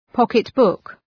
{‘pɒkıt,bʋk}
pocketbook.mp3